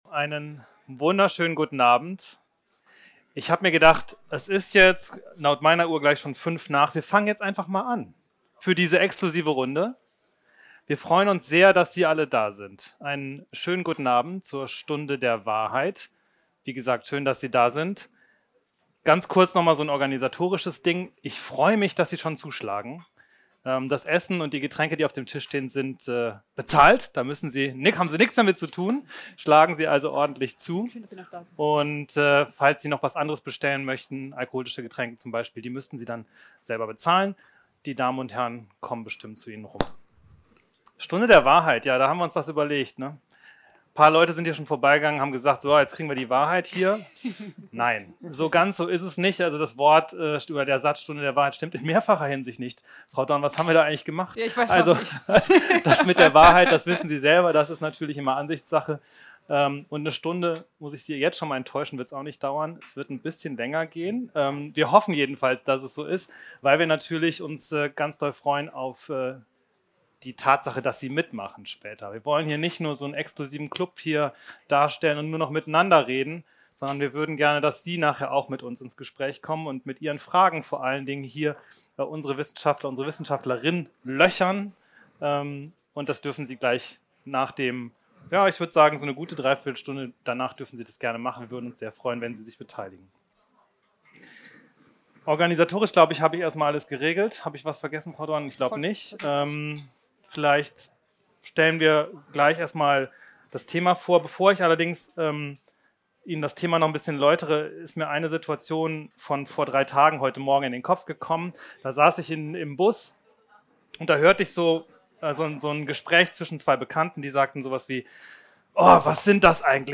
In der Veranstaltungsreihe „Die Stunde der Wahrheit“ lädt Wissenschaftsministerin Angela Dorn Wissenschaftlerinnen und Wissenschaftler ein, damit Bürgerinnen und Bürger mit ihnen am Stammtisch ins Gespräch kommen können, diemal nach Wetzlar.
sdw_wetzlar_mitschnitt_v0.mp3